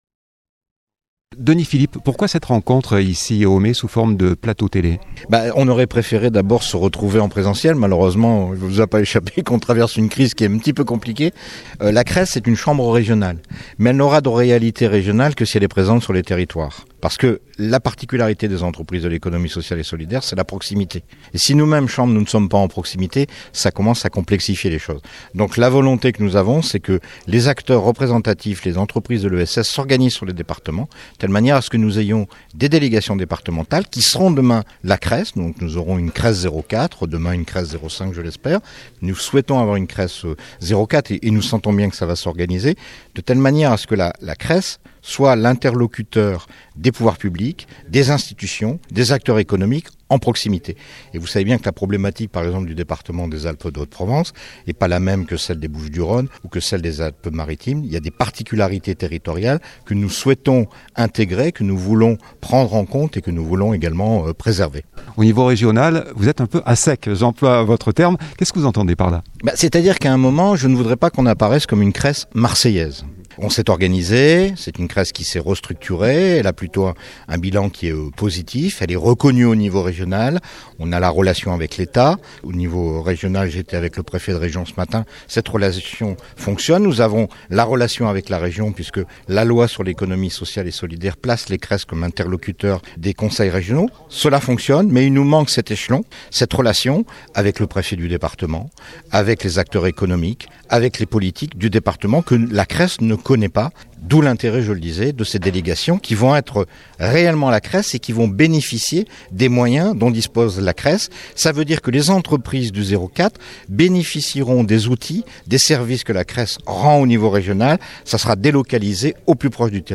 La semaine dernière dans la salle des fêtes des Mées se déroulait, sous forme de plateau télé en v isioconférence, la rencontre information présentation, proposée par la CRESS ( Chambre Régionale de l’Economie Sociale et Solidaire ) . O bjectif de cette rencontre : un dialogue autour du plan de relance pour l’Economie S ociale et S olidaire et les nouveaux dispositifs de soutien mis en place à l’attention de se s diverses structures.